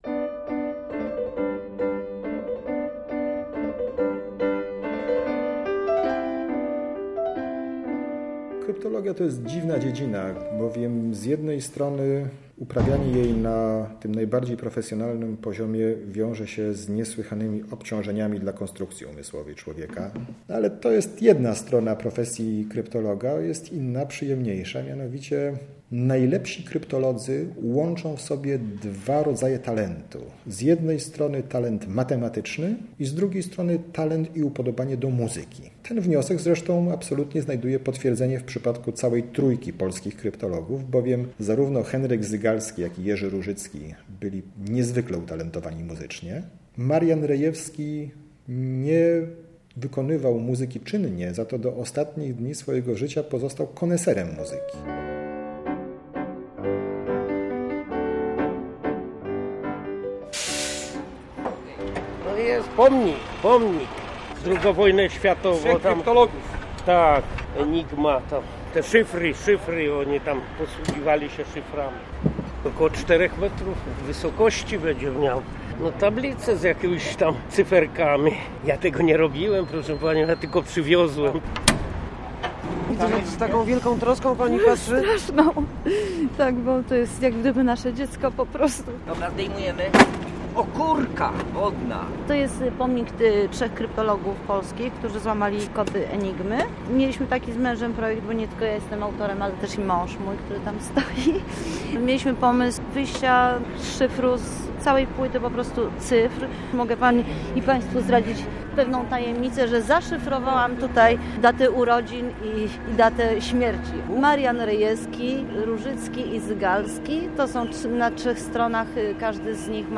Reportaż 12 listopada 2011 - Radio Poznań